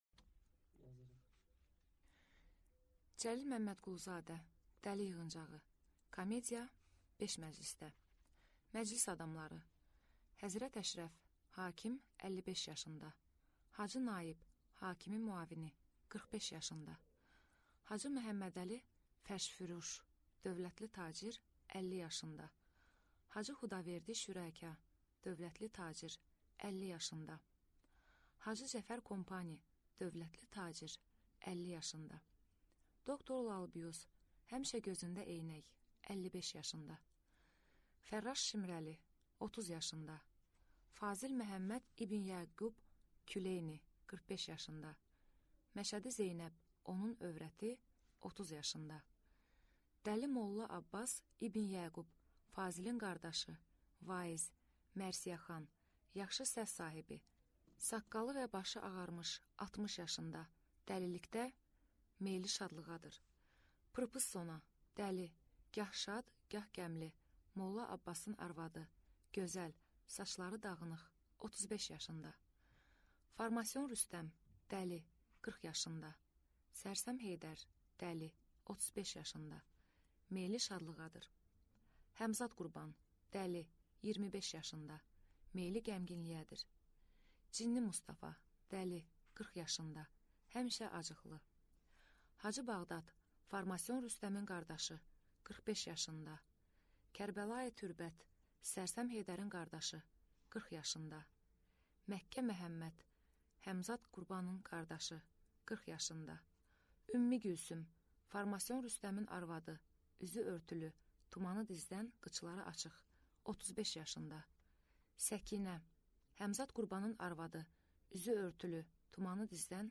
Аудиокнига Dəli yığıncağı | Библиотека аудиокниг
Прослушать и бесплатно скачать фрагмент аудиокниги